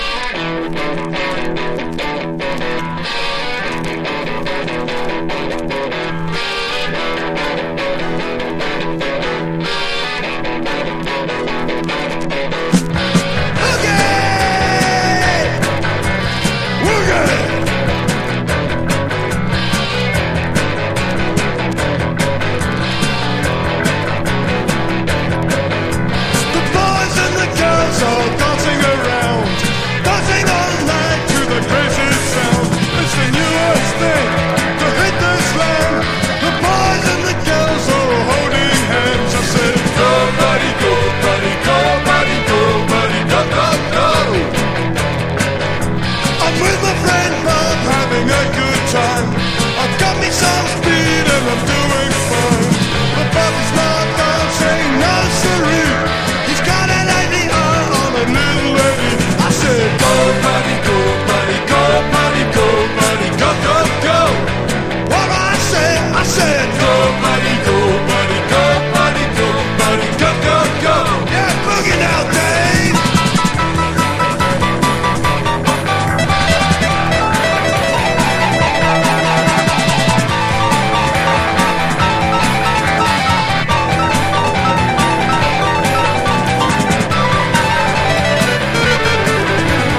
# NEW WAVE# 70’s ROCK# 80’s ROCK
所によりノイズありますが、リスニング用としては問題く、中古盤として標準的なコンディション。